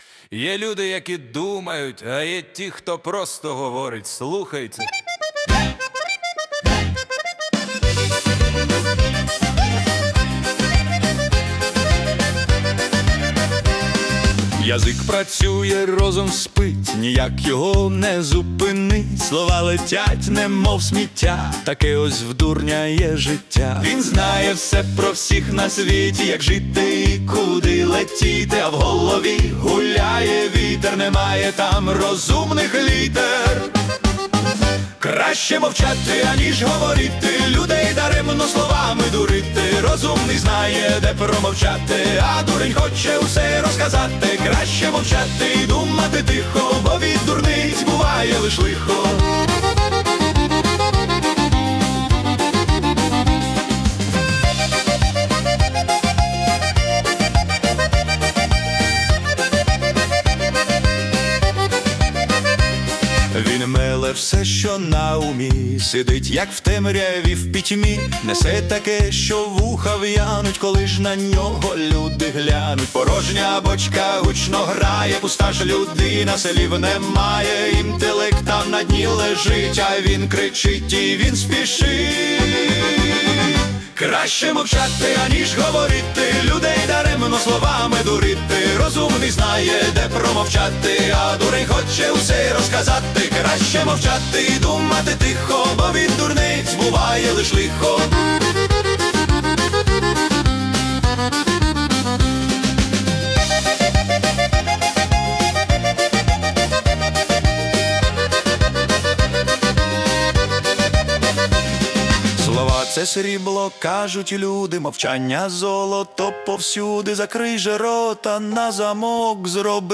Життєва мудрість у ритмі джазу